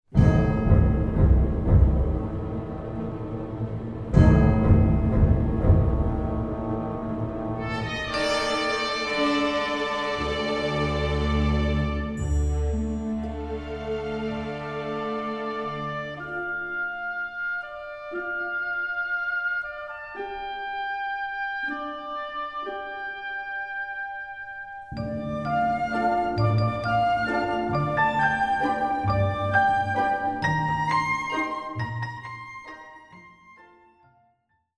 World Premiere Recording